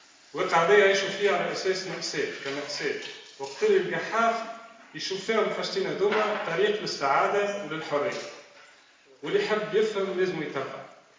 TEDx_large_room.mp3